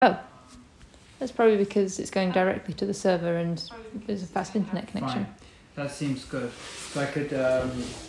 Live from Soundcamp: Wave Farm Radio (Audio)